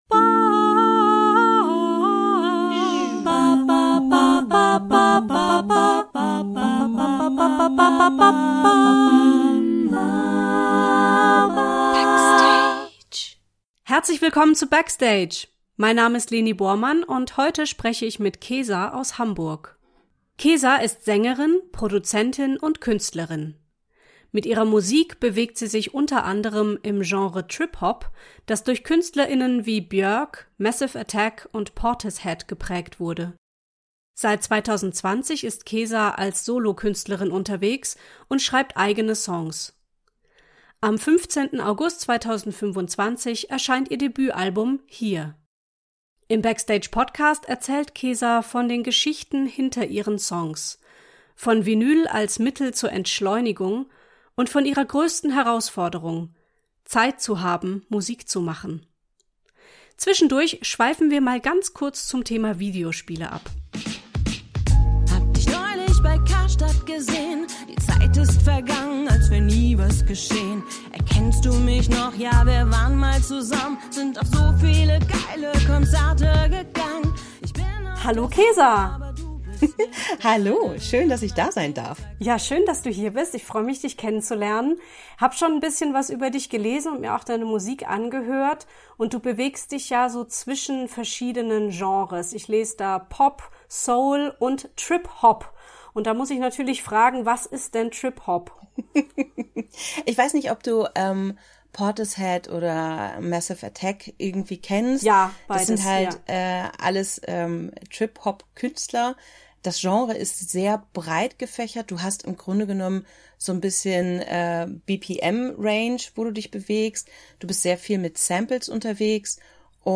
Der BACKSTAGE! Podcast stellt Künstler*innen aus verschiedenen Sparten vor und blickt hinter die Kulissen. Es geht um die Entstehung kreativer Projekte und die Menschen dahinter, Werdegänge, Inspirationsquellen, Ziele, Sorgen und Wünsche. Dabei sind die lockeren Gespräche kein reines Frage-Antwort-Interview, es gibt durchaus auch mal die eine oder andere Abschweifung.